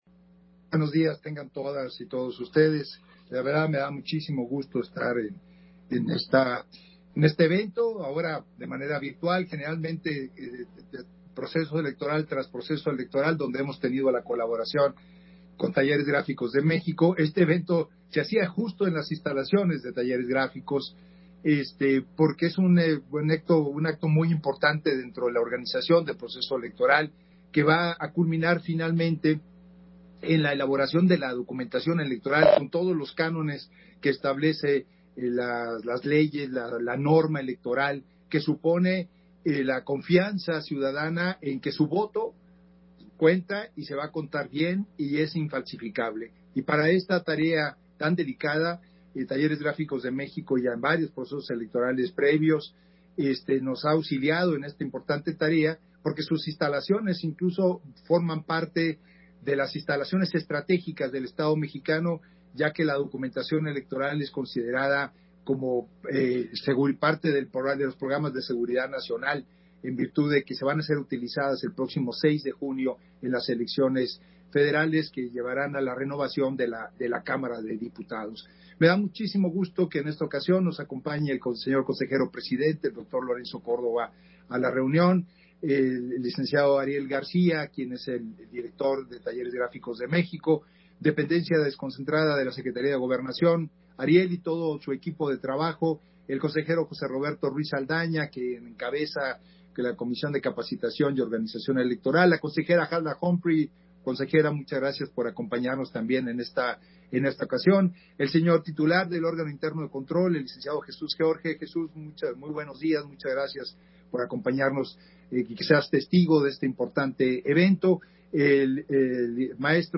Intervención de Edmundo Jacobo, en la firma de Convenio de Colaboración Técnica, INE-Talleres Gráficos de México